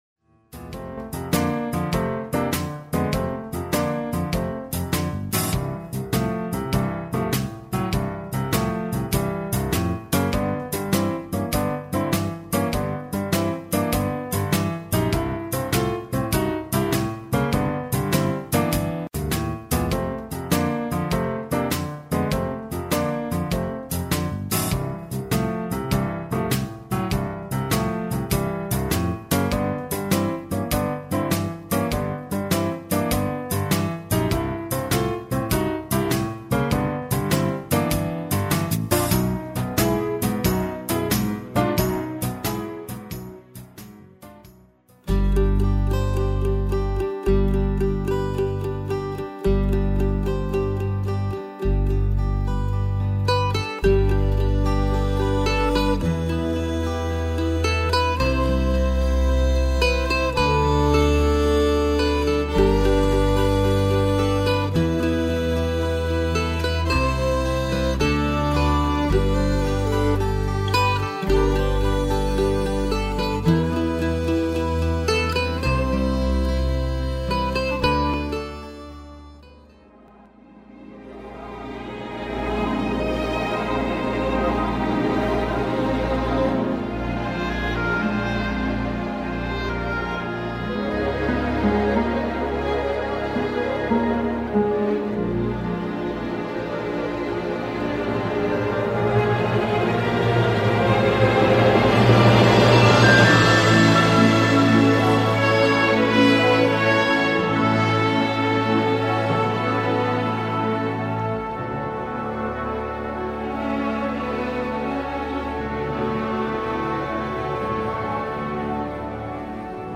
Auf das „Glitzern“ in der Musik, bleiben die Kids dann im Raum stehen und beugen sich vor, um den Nektar zu saugen.